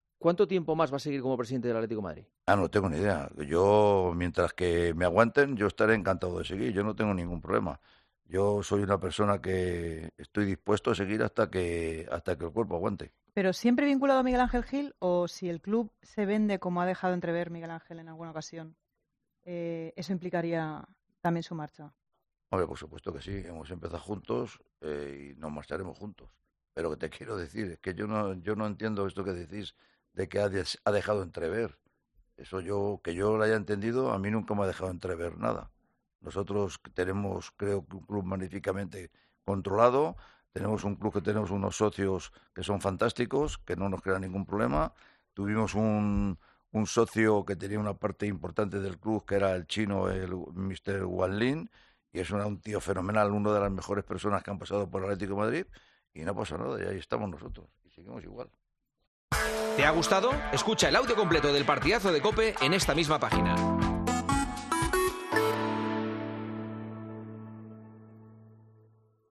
Todas estas vivencias las repasó este miércoles con Juanma Castaño en una entrevista en El Partidazo de COPE.